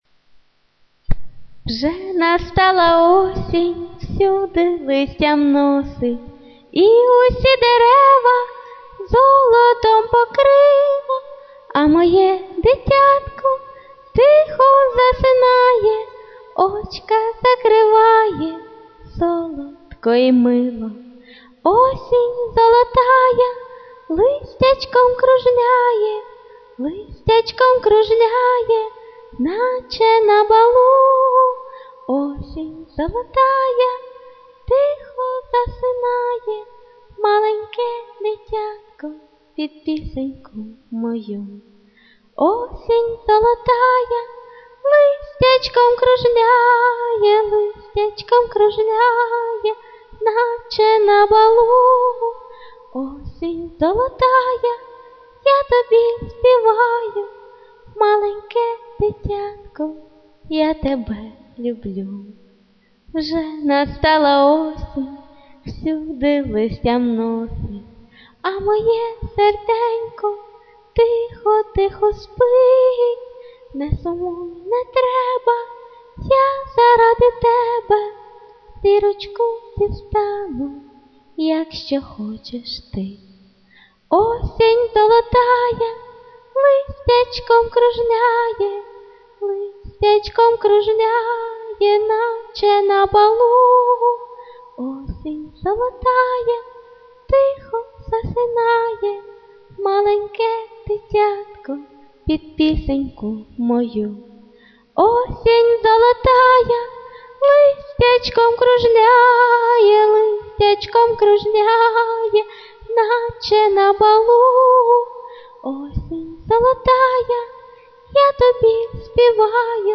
clap Трепетно,от души,голосок срывается,дрожит,..но в этом есть свой шарм и подкупляет!..Молодчина! flo12
красивый у Вас голос. give_rose
Гарна колискова!
Голосочок - ніжний.